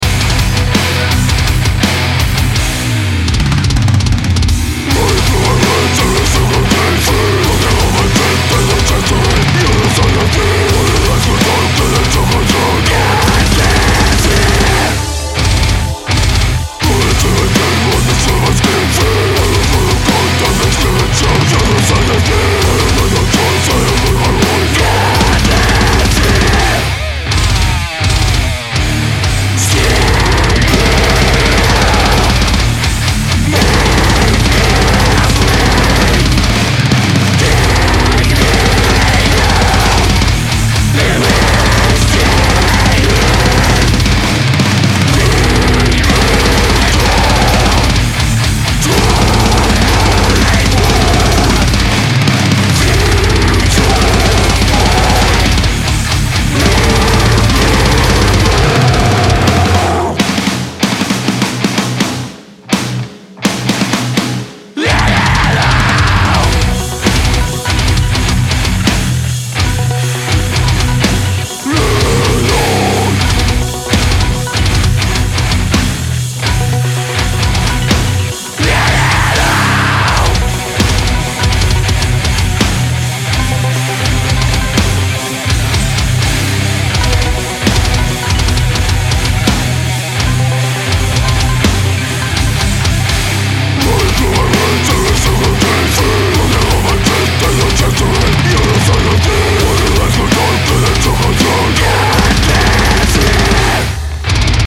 ������, ��� ����� � ;D��������� ������ �������� ��������� ������� �������.������: ts808 --> LeCto--> Sir2 � ts808 --> Le456--> ...